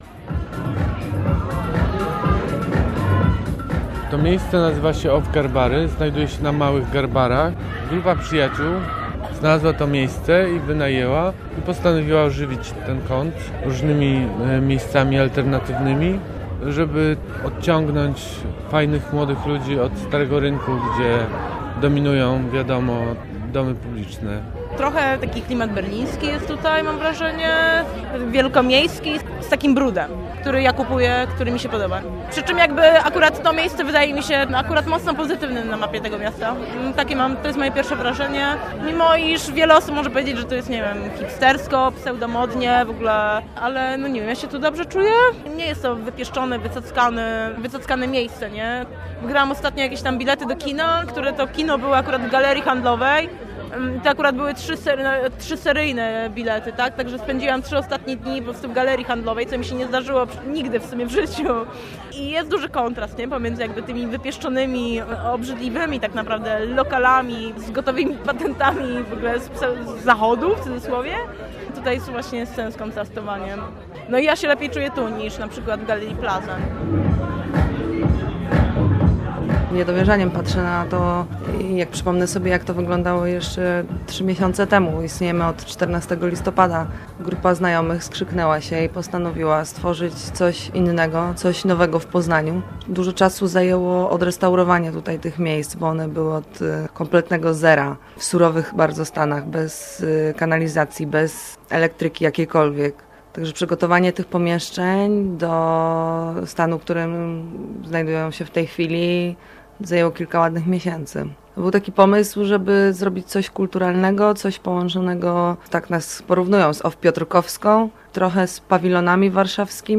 OFF Garbary - reportaż